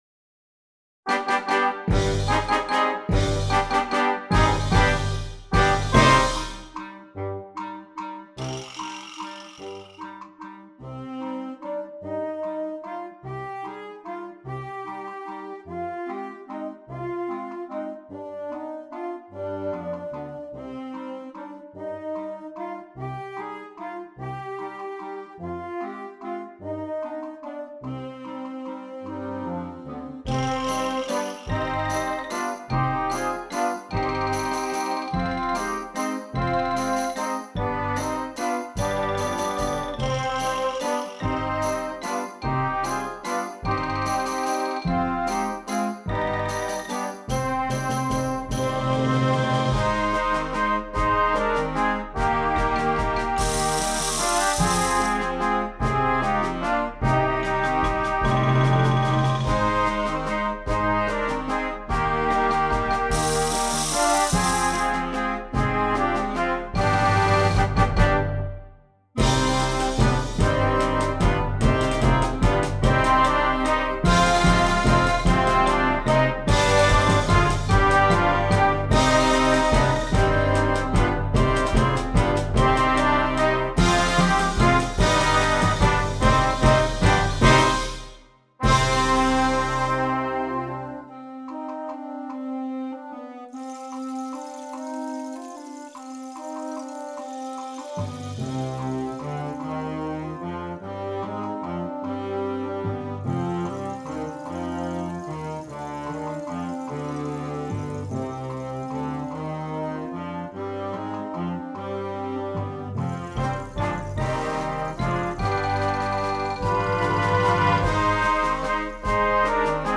Concert Band
Set in a minor mode and using a waltz metre